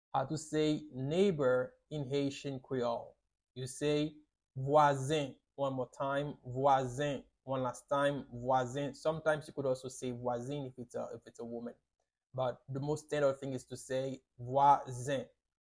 How to say "Neighbor" in Haitian Creole - "Vwazen" pronunciation by a native Haitian tutor
How-to-say-Neighbor-in-Haitian-Creole-Vwazen-pronunciation-by-a-native-Haitian-tutor.mp3